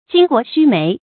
巾幗須眉 注音： ㄐㄧㄣ ㄍㄨㄛˊ ㄒㄩ ㄇㄟˊ 讀音讀法： 意思解釋： 巾幗：古代婦女配戴的頭巾和發飾，后借指婦女。